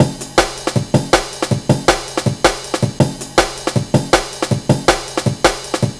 Index of /90_sSampleCDs/Zero-G - Total Drum Bass/Drumloops - 1/track 11 (160bpm)